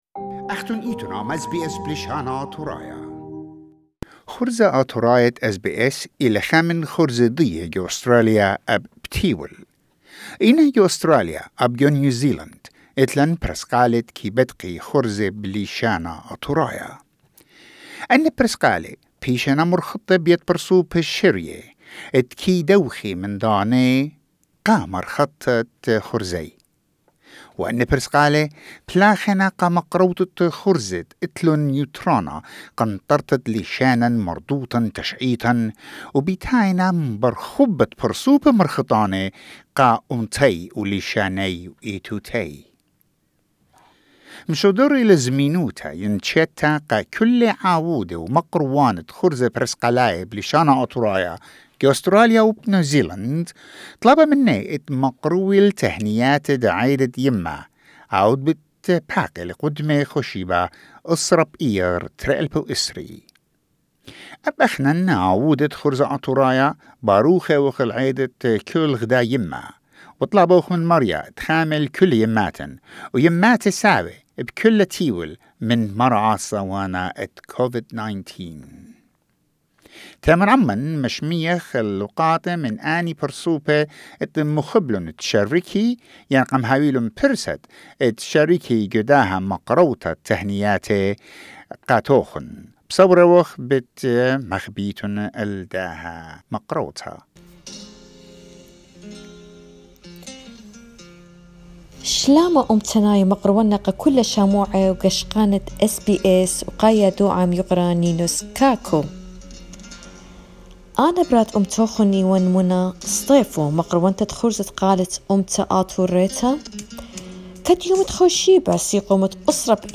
Assyrian radio broadcasters from Sydney, Melbourne and Auckland, NZ, paid tribute to mother's day by expressing their true feeling towards their own mum and their nation's mother